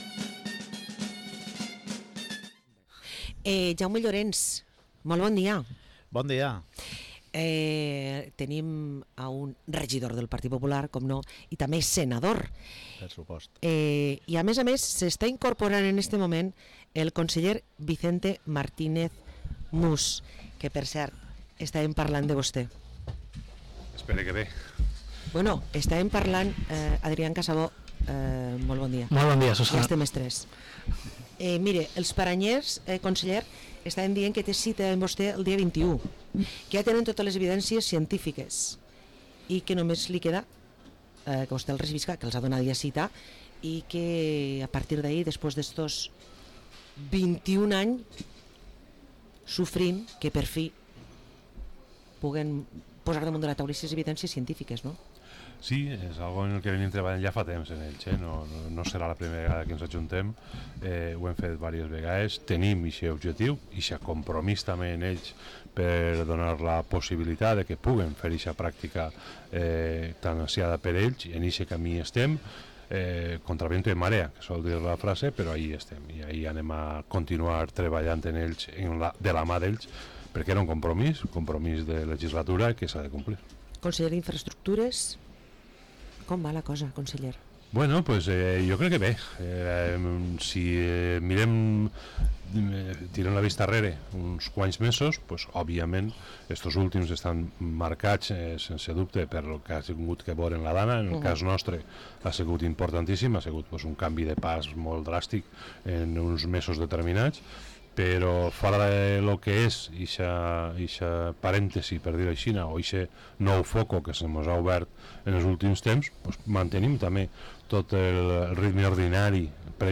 Hui el regidor i portaveu del PP a Vila-real ens ha visitat amb el Conseller Vicente Martínez Mus i el Senador Jaume Llorens